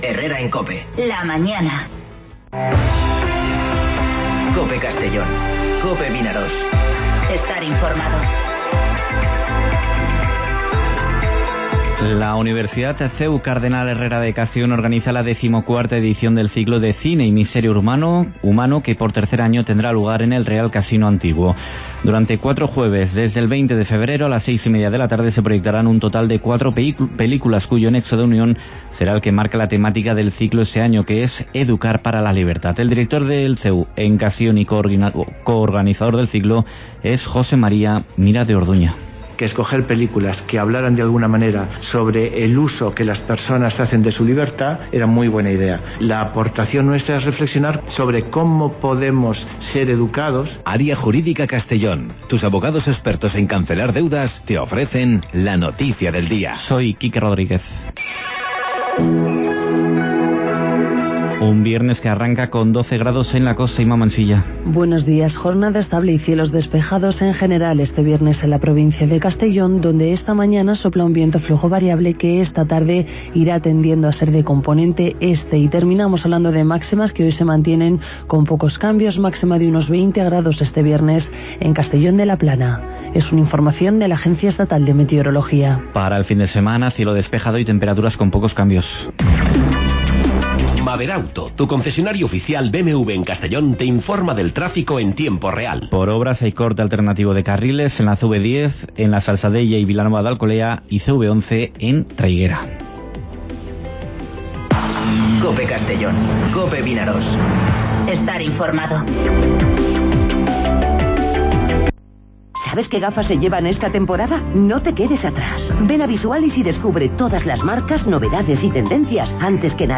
Informativo Herrera en COPE Castellón (14/02/2020)